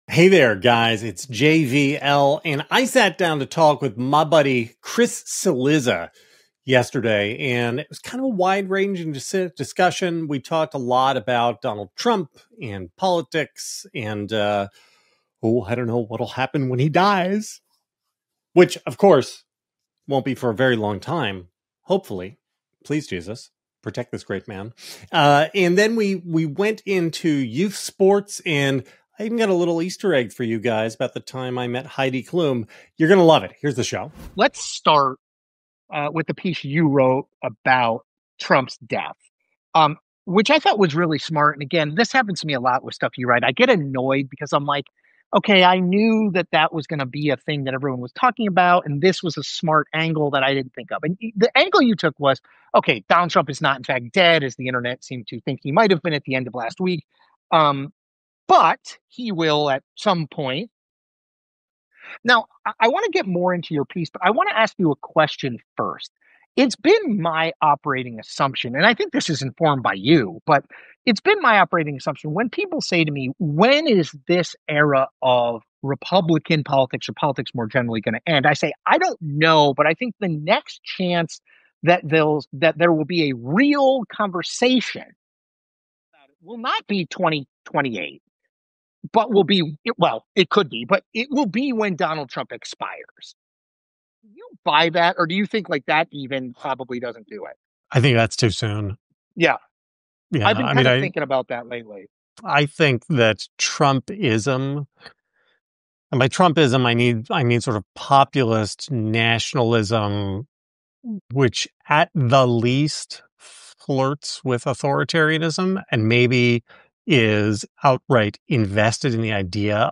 a conversation on Trump’s future, authoritarian politics, JD Vance’s ambitions, Don Jr.’s role, and why pro wrestling explains Trump better than anything else.